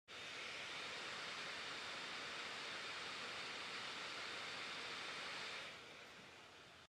Using my old pal iStat Menus I was able to monitor the fan speed and see it jump from under 2000RPM before engaging Audio Hijack to over 5000RPM. I haven’t done a decibel test, but the 16” fans at 5000RPM are stupid loud.
Fans_1622_Macbook_Pro.mp3